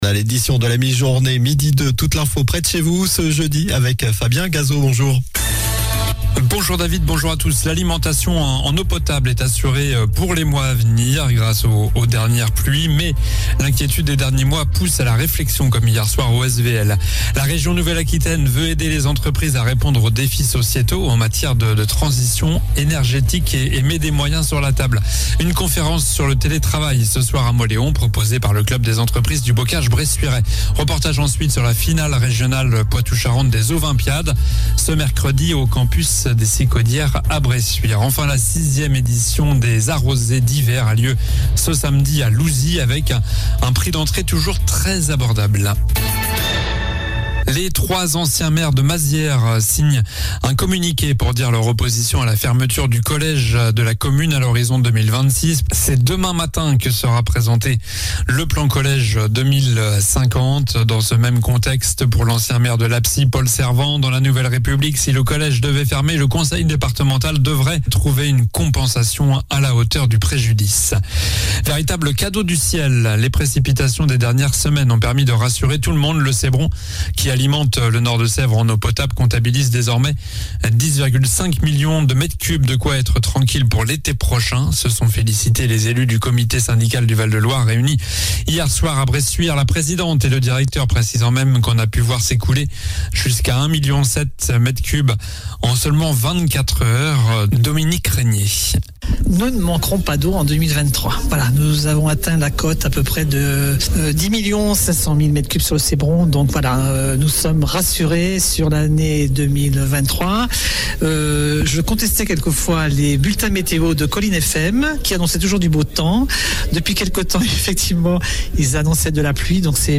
Journal du jeudi 02 février (midi)